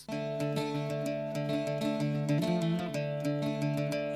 Discover the Irish Bouzouki
Experience the Sound of the Irish Bouzouki press play below